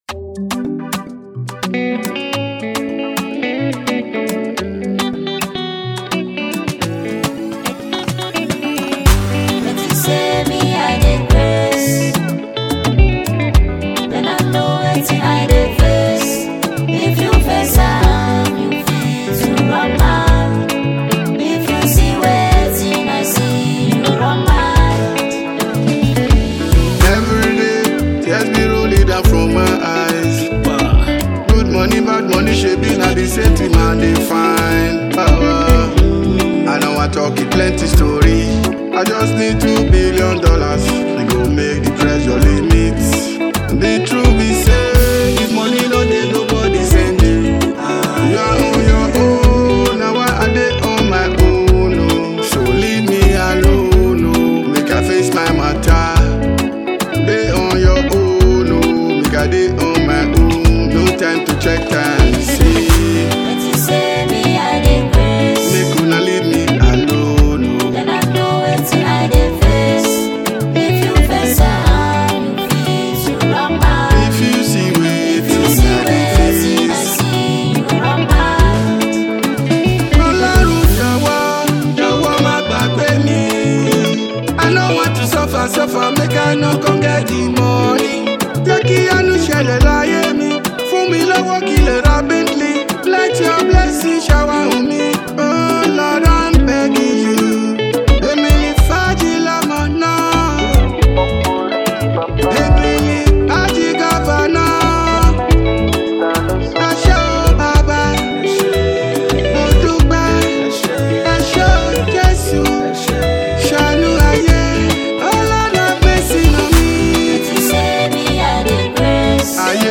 soul-stirring track